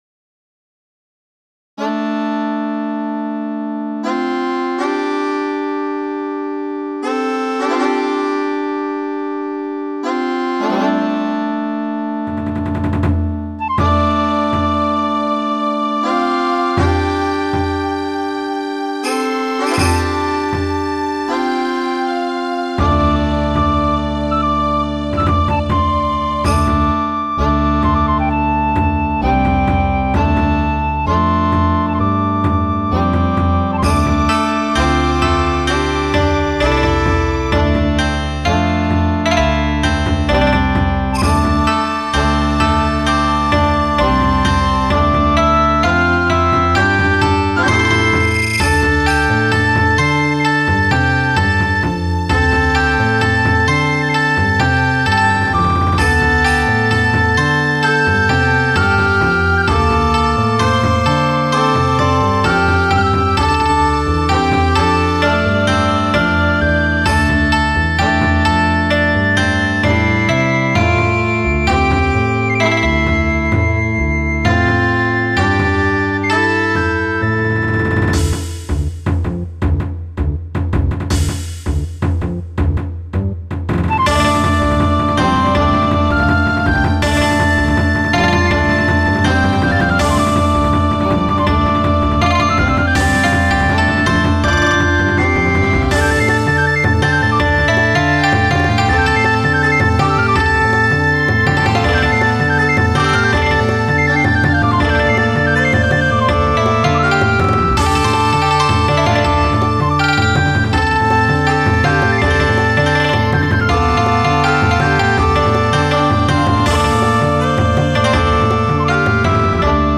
ゆったりしたイントロが終わると、ちょっと激しい感じになります。和風？ 中華風？ 太鼓どこどん。
1ループフェードアウト。